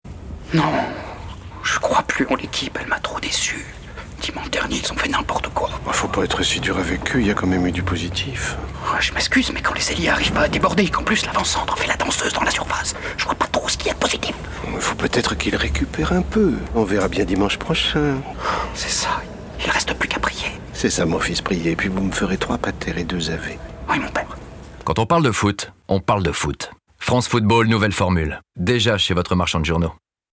Pub Radio - FranceFootball Campagne - voix 2 (le Confessé)